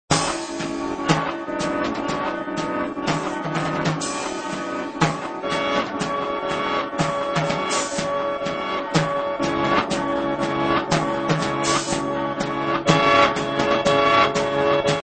improvisations